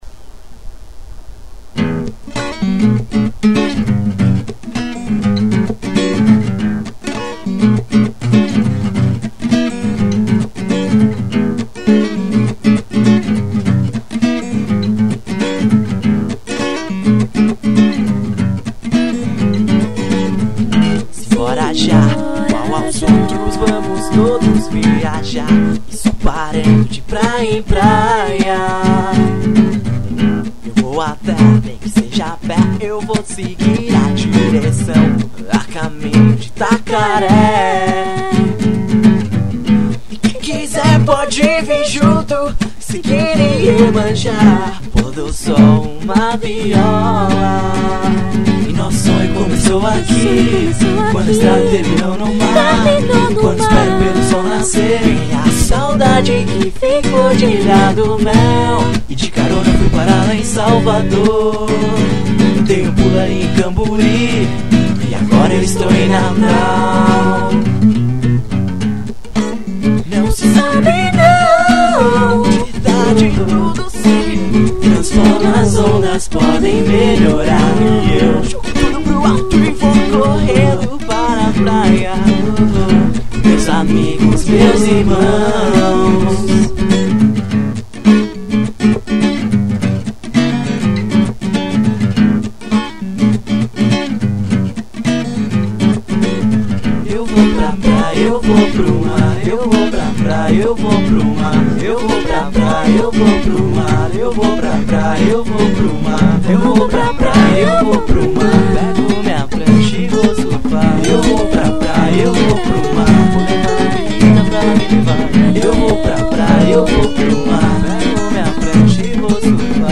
EstiloSurf Music